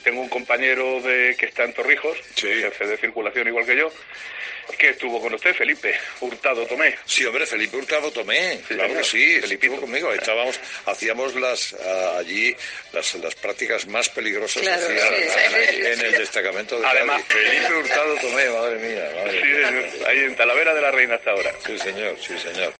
Carlos Herrera habla en su programa